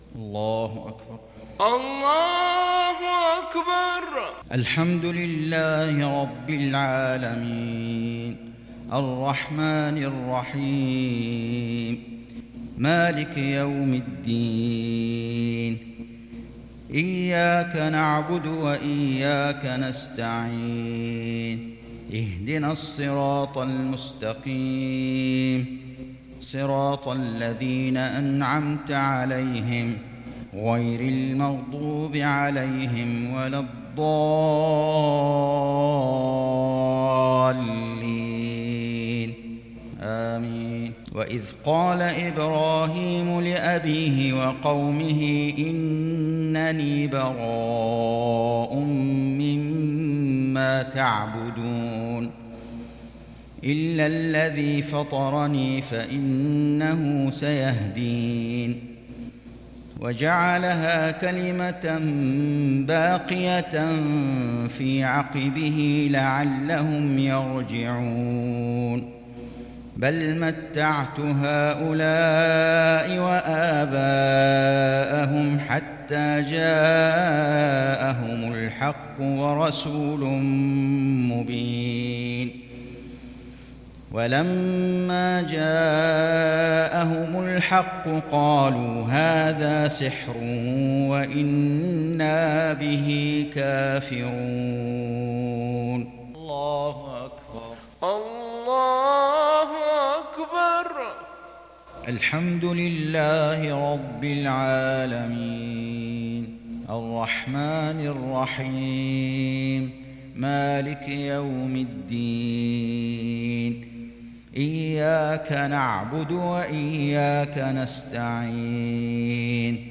صلاة المغرب للشيخ فيصل غزاوي 24 صفر 1442 هـ
تِلَاوَات الْحَرَمَيْن .